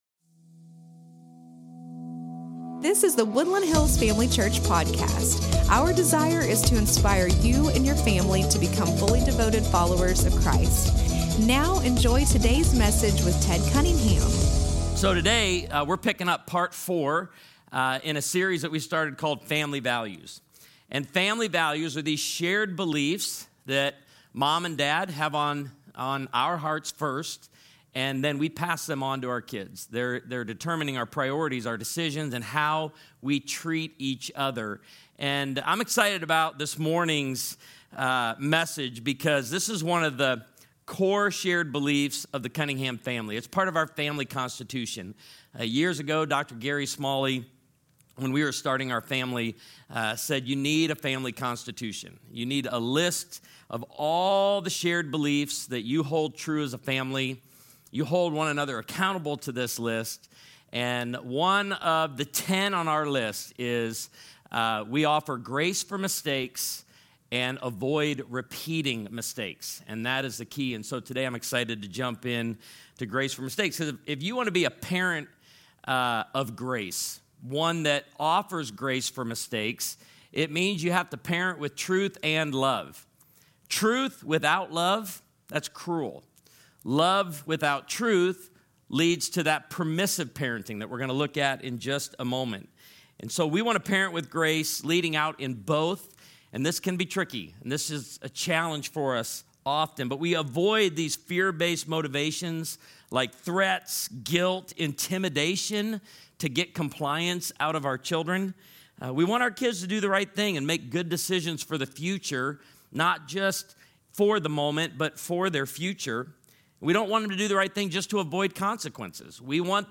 Podcast_SERMON ONLY.mp3